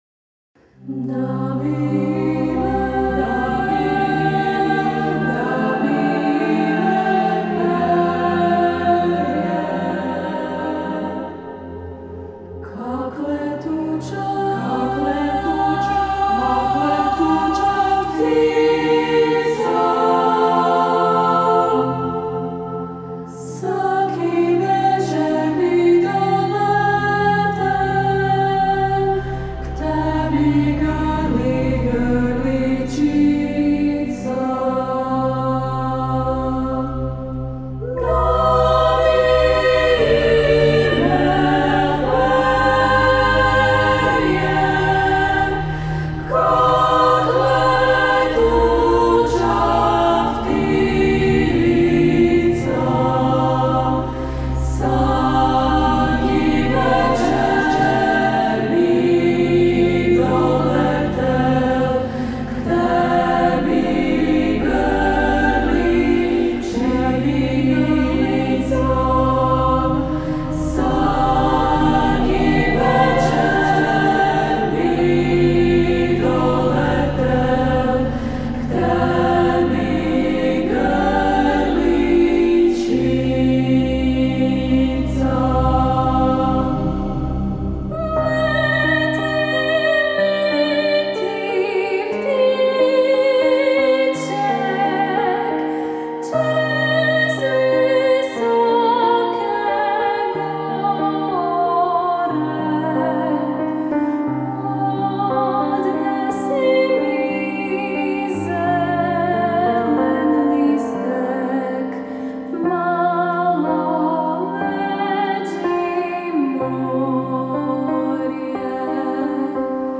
Ženska vokalna skupina „Pučpuljike“ izdala svoj prvi spot
Dokaz tome je i promocija prvog spota ženske vokalne skupine „Pučpuljike“ koja djeluje od 2019. njegujući kulturno – umjetnički amaterizam kroz tradicijske folklorne napjeve i umjetničke obrade bogate hrvatske i strane glazbene scene.
tradicijskoj međimurskoj pjesmi